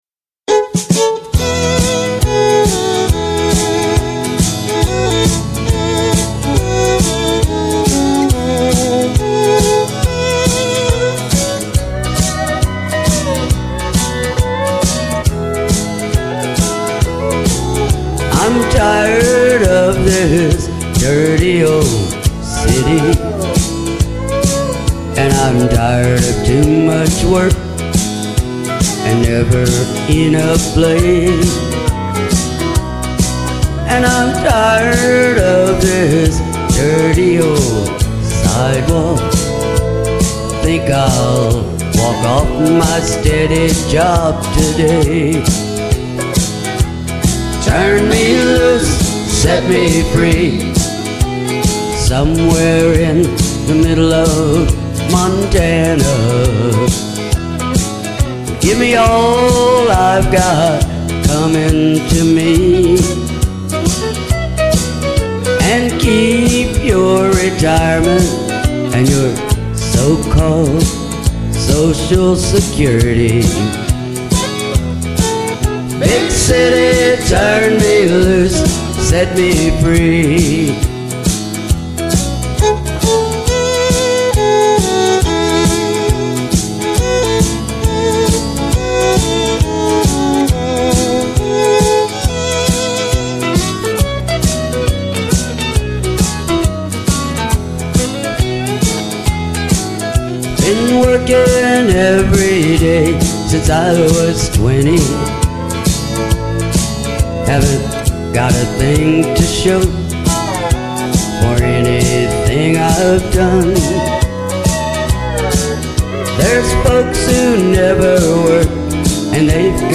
Classic Country, Old Rock N' Roll, Country Gospel, and Blues